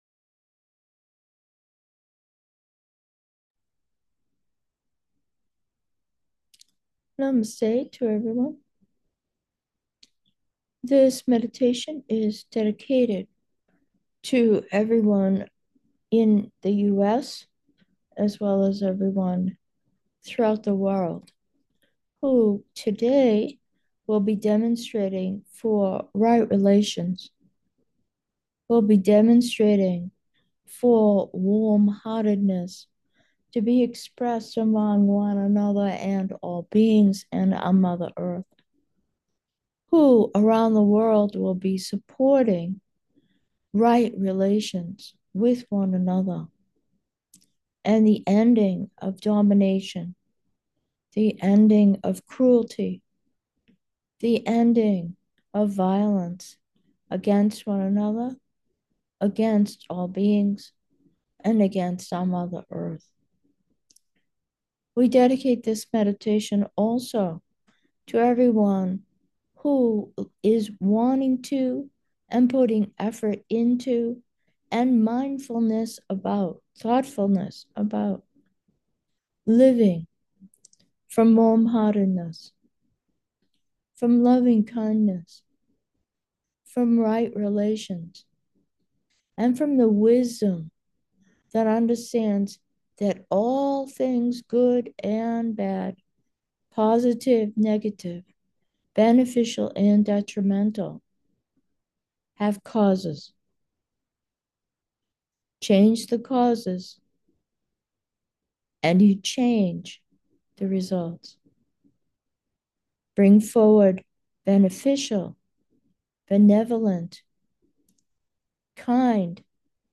Meditation: in support of demonstrations: equanimity, light, and the power of the heart